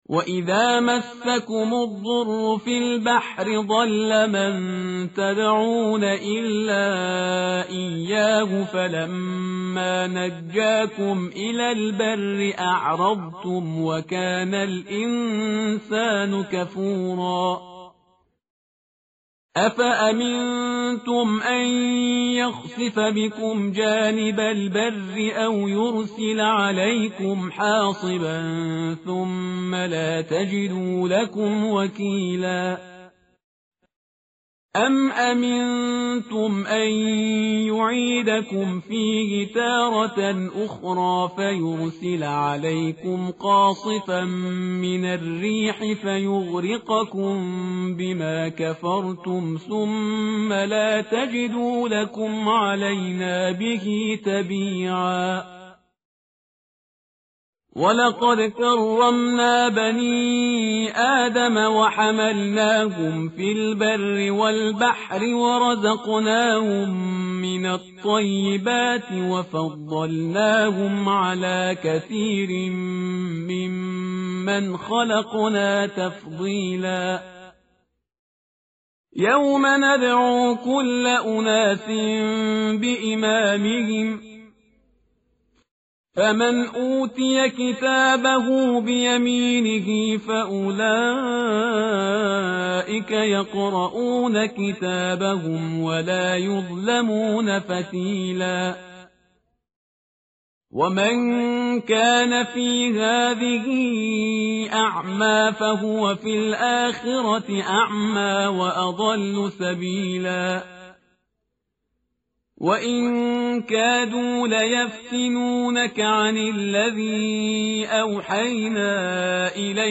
tartil_parhizgar_page_289.mp3